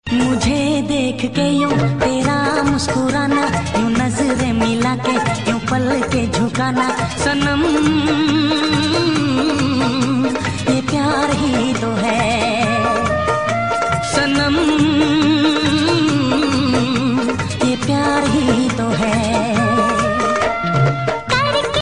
Beautiful romantic ringtone for mobile.